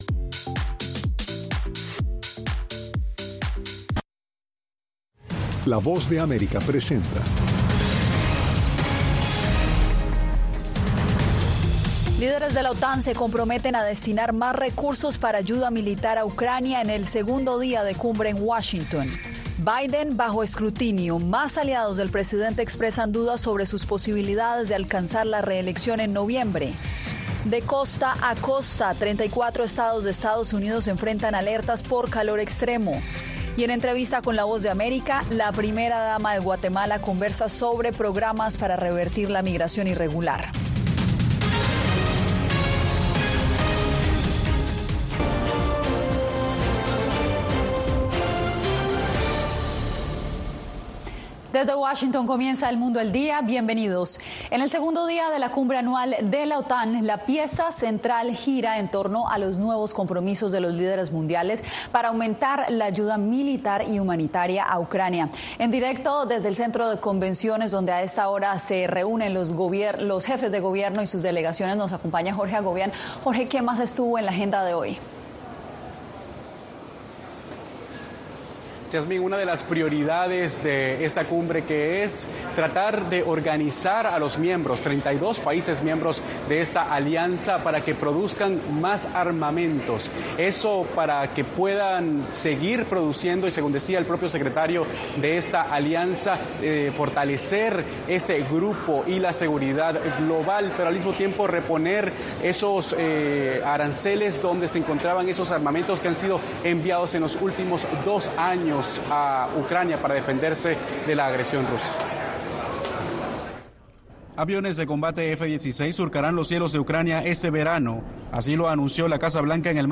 Líderes de de la OTAN se comprometen a destinar más recursos para ayuda militar a ucrania, en el segundo día de cumbre en Washington. Y en entrevista con la Voz de América, la primera dama de Guatemala conversa sobre programas para revertir la migración irregular.